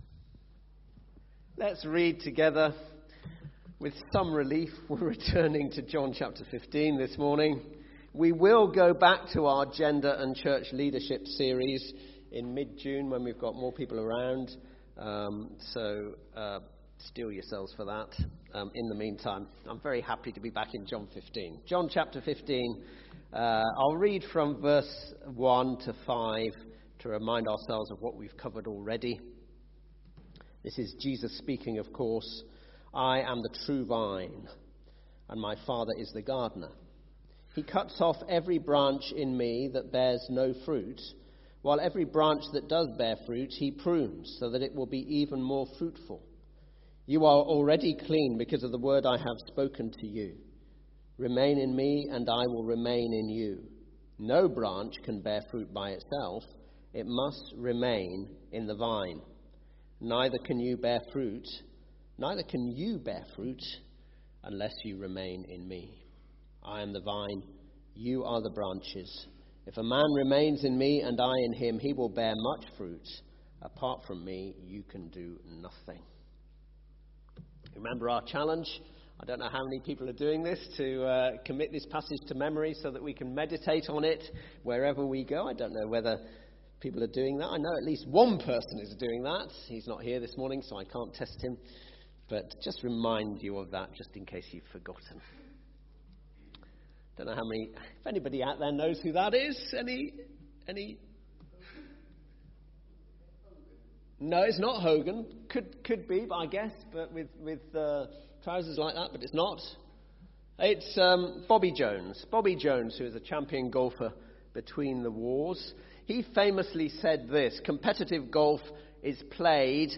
speaks on Remain In Me from John 15:4.